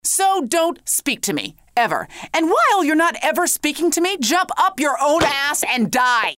Next, remember when we said Lana talks? We’ll, we also have some of the phrases that will be included in the final product, voiced by Lana herself, Aisha Tyler.
Lana-Dont-speak-to-me.mp3